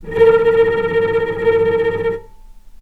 healing-soundscapes/Sound Banks/HSS_OP_Pack/Strings/cello/tremolo/vc_trm-A#4-pp.aif at b3491bb4d8ce6d21e289ff40adc3c6f654cc89a0
vc_trm-A#4-pp.aif